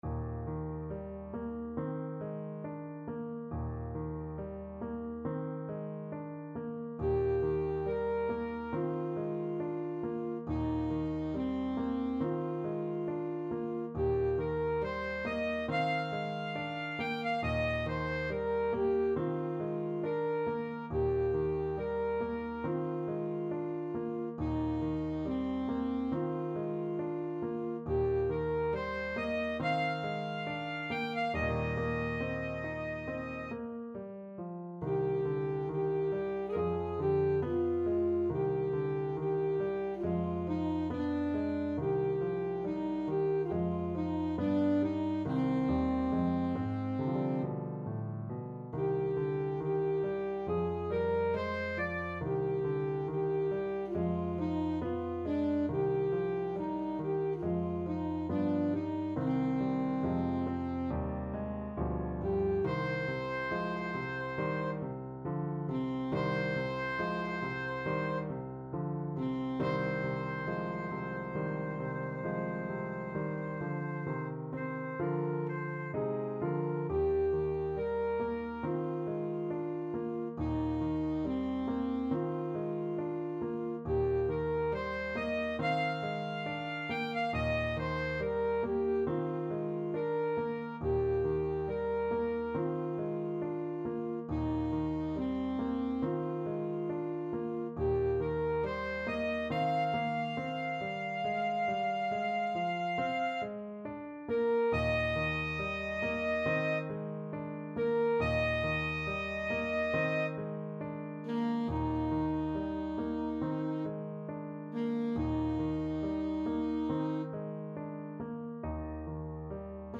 Classical Ilyinsky, Alexander Berceuse from Noure et Anitra Op.13, No.7 Alto Saxophone version
Alto Saxophone
Eb major (Sounding Pitch) C major (Alto Saxophone in Eb) (View more Eb major Music for Saxophone )
~ =69 Poco andante
4/4 (View more 4/4 Music)
Classical (View more Classical Saxophone Music)